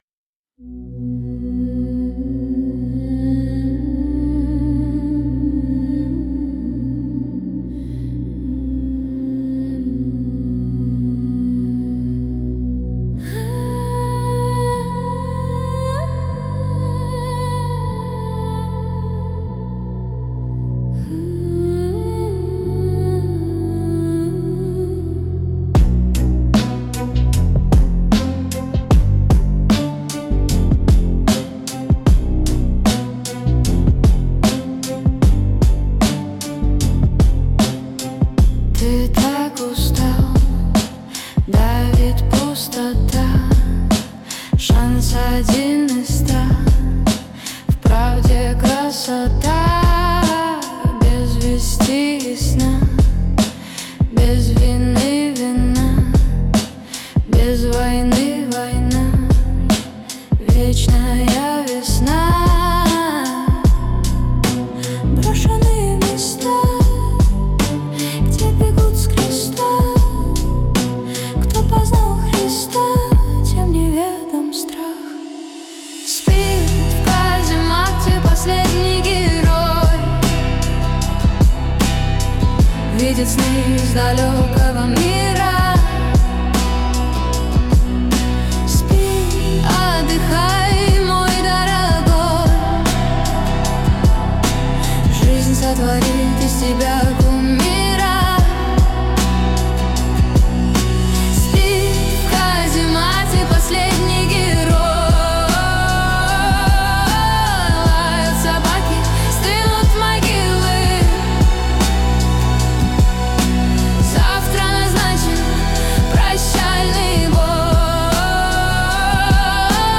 Hybrid trip-hop grunge. Start with a soft, delicate, almost whispery female voice over dark atmospheric pads, deep bass, and a slow trip-hop beat with muted guitars